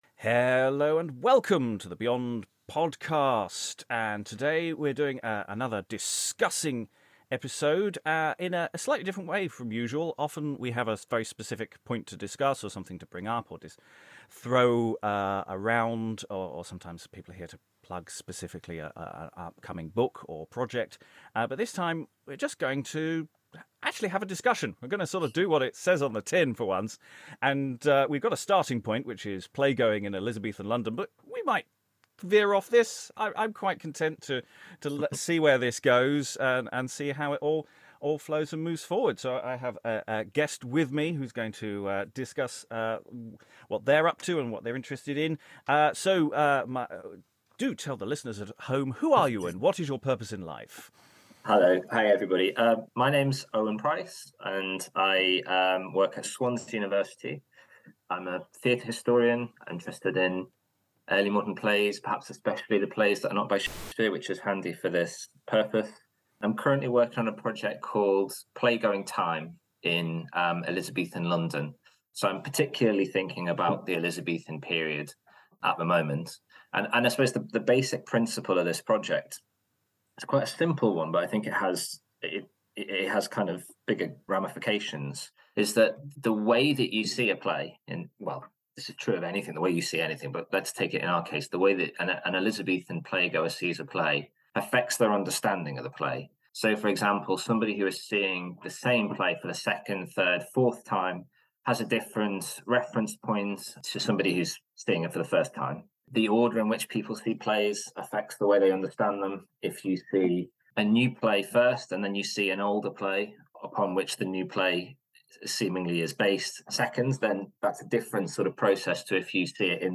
A chat about going to the theatre in the reign of Elizabeth, amongst other things.